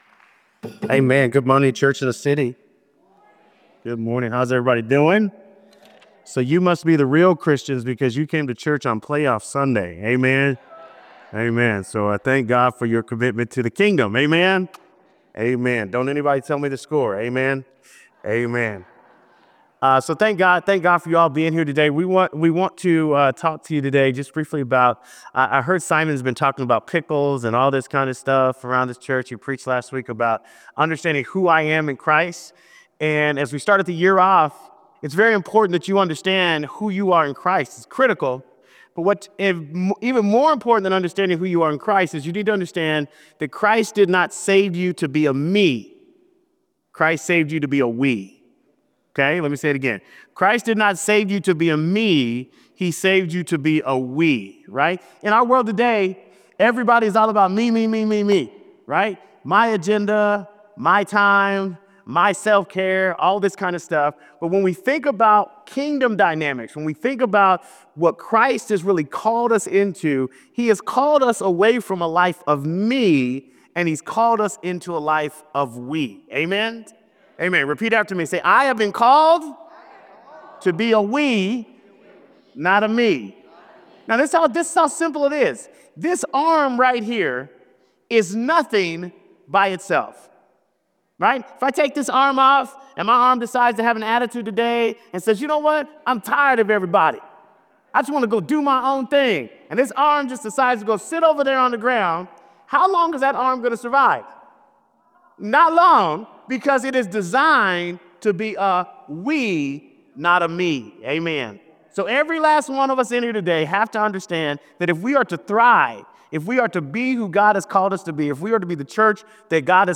Replay of the weekend services